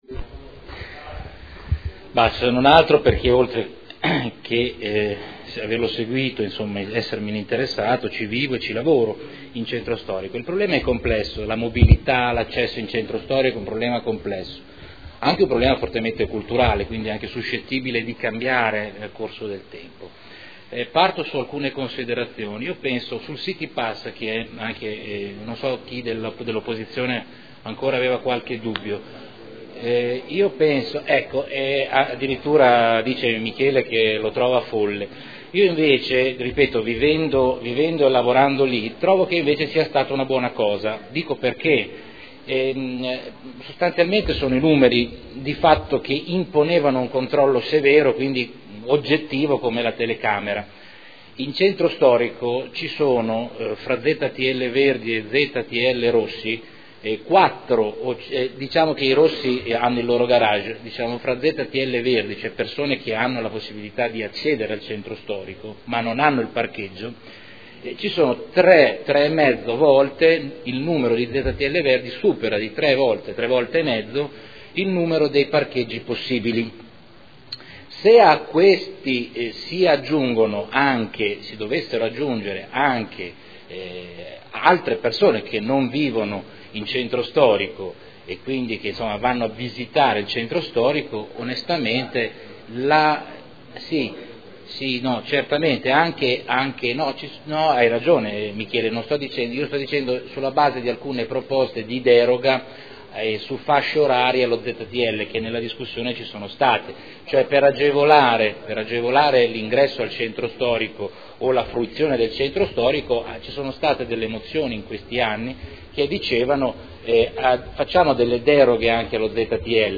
Salvatore Cotrino — Sito Audio Consiglio Comunale